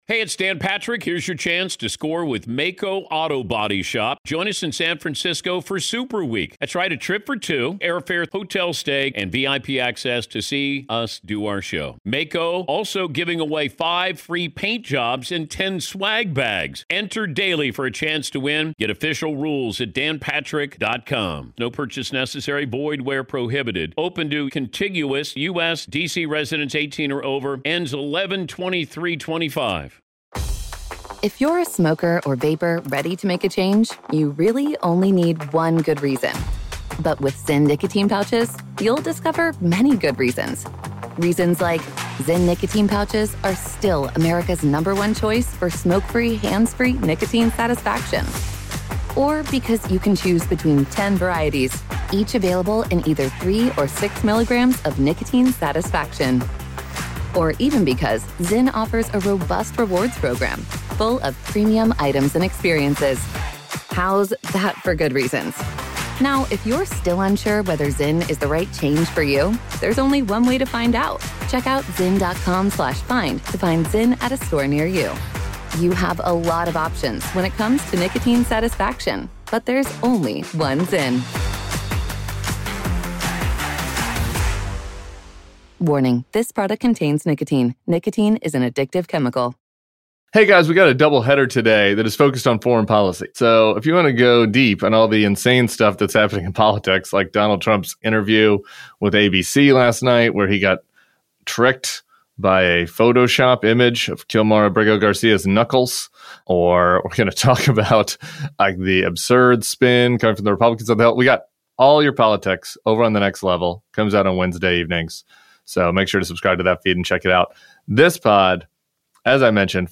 Former Secretary of State Antony Blinken joins Tim to discuss how hard it will be to rebuild after what Trump has done—plus the Biden administration's response to the Gaza protests, and the continuing questions over why Biden did not step aside earlier. And, Alex Wagner joins from Hungary to discuss the mass protests there. Tony Blinken and Alex Wagner join Tim Miller.